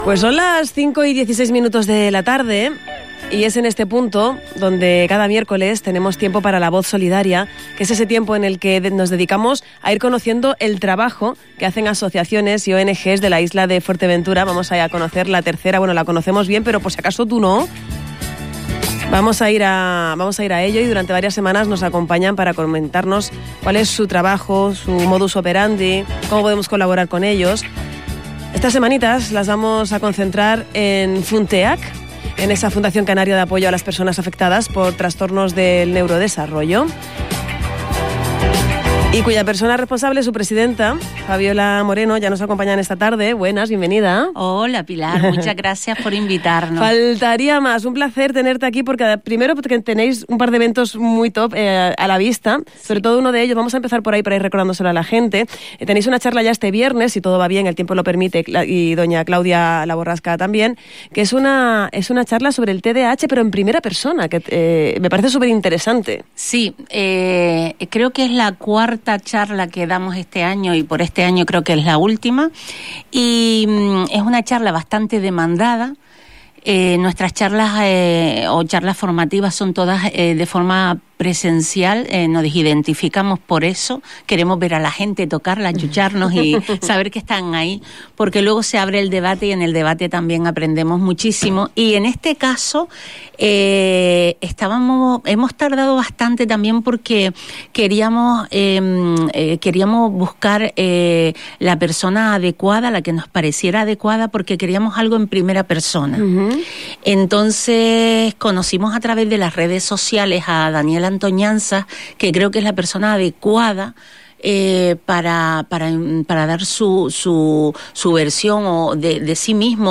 En una entrevista en El Tardeo